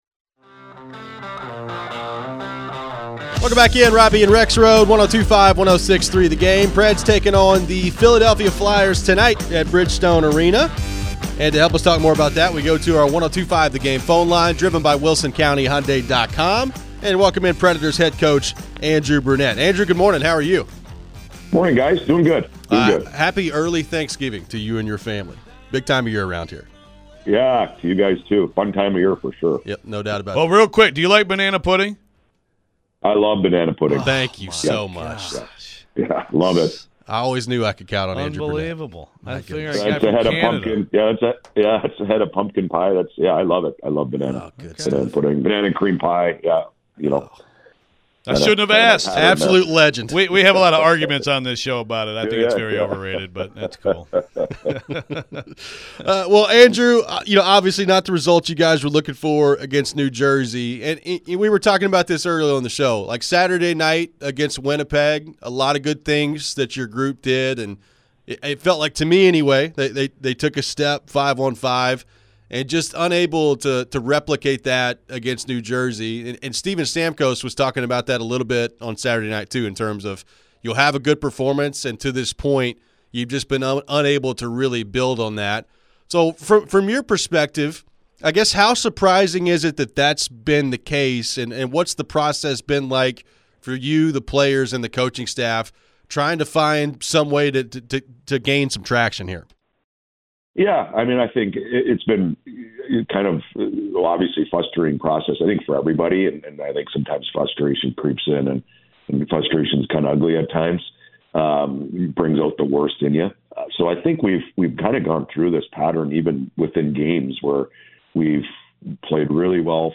Andrew Brunette Interview (11-27-24)
Nashville Predators head coach Andrew Brunette joined the show to discuss the latest on his team. What's played into the inconsistent play 22 games into the season? How can the Predators find their game?